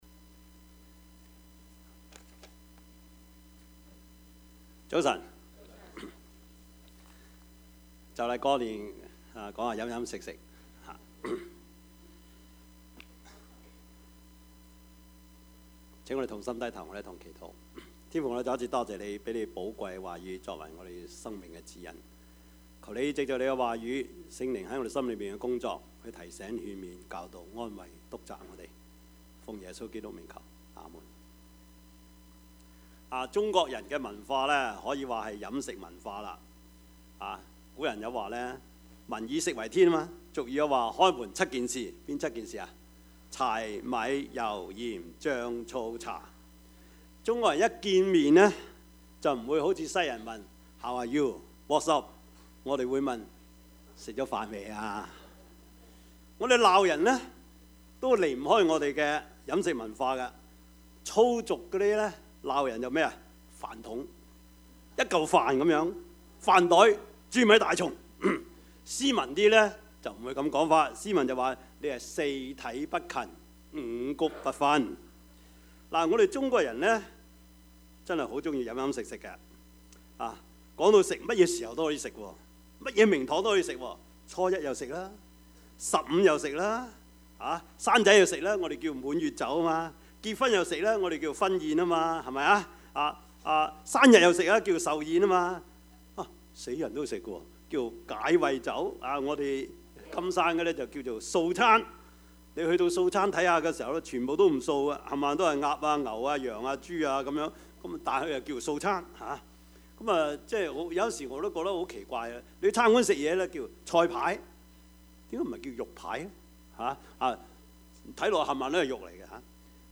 Passage: 路加福音 14:1-14 Service Type: 主日崇拜
Topics: 主日證道 « 終點的喜樂 事就這樣成了 »